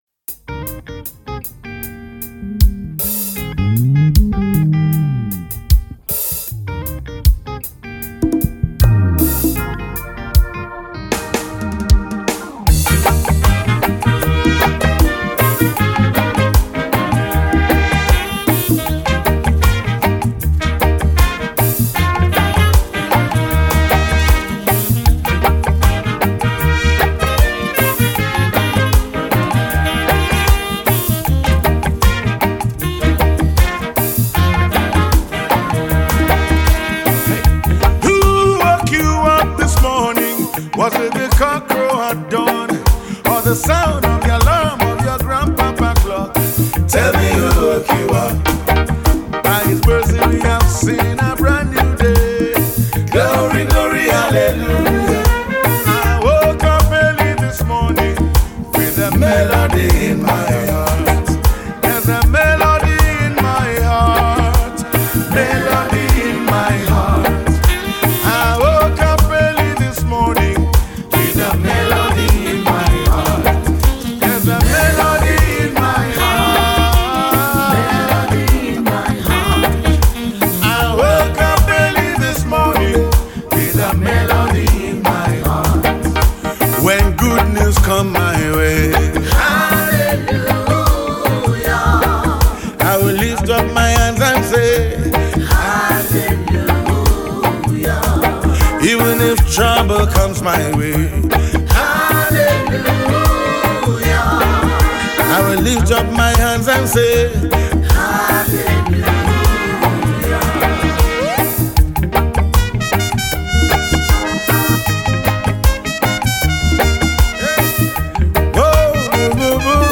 Gospel
poet spoken words and reggae gospel artist.